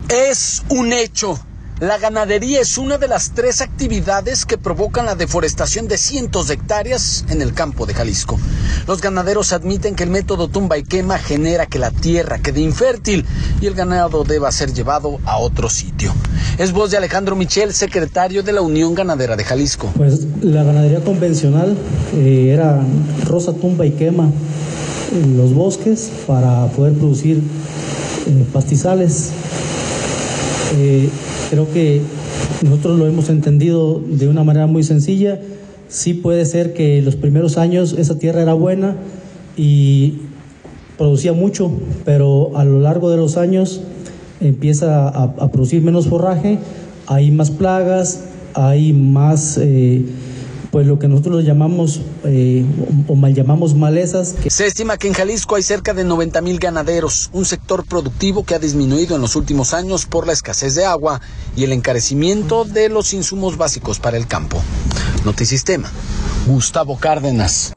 Es un hecho, la ganadería es una de las tres actividades que provocan la deforestación de cientos de hectáreas en el campo de Jalisco. Los ganaderos admiten que el método tumba y quema genera que la tierra quede infértil y el ganado deba ser llevado a otro sitio a pastar. Es voz de